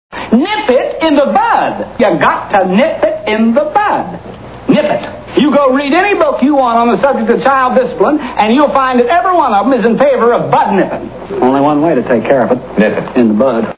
Andy Griffith Show Sound Bites (Page 3 of 4)